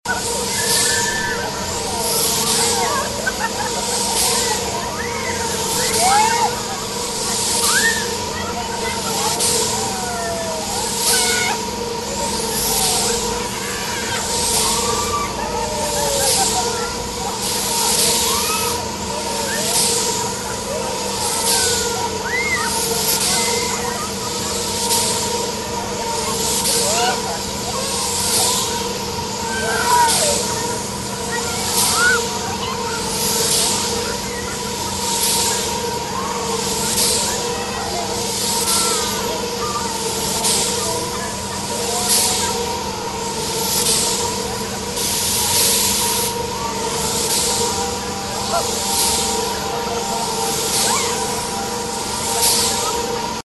Звуки парка аттракционов
Звук веселого смеха гостей на аттракционах парка